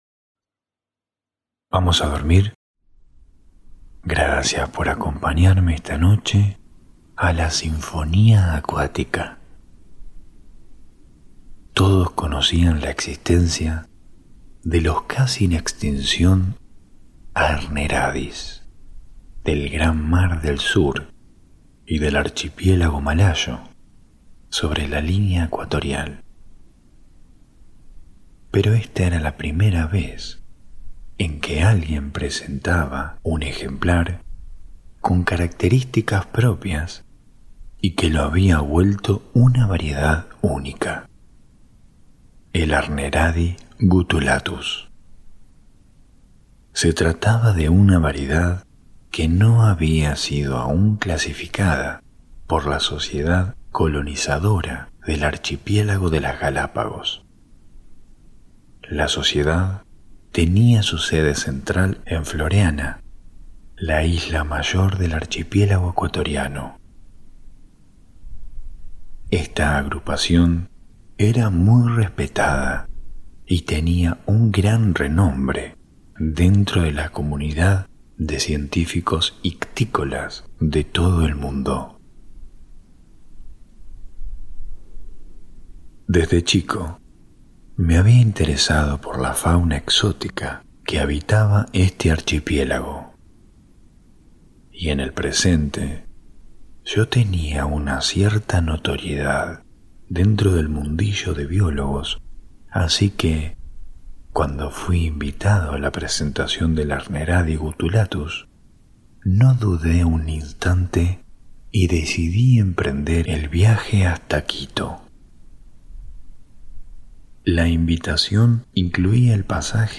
Cuentos ASMR para dormir - Sinfonía Acuática ⚗